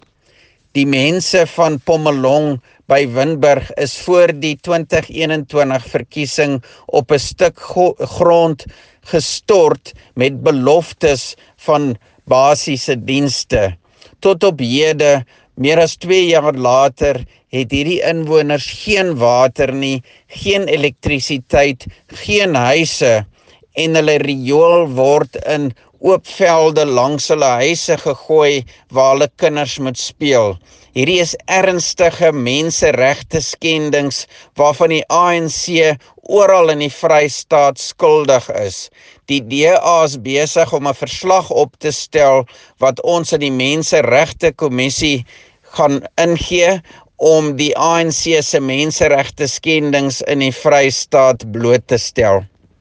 Afrikaans soundbites by Roy Jankielsohn MPL as well as images, here, here and here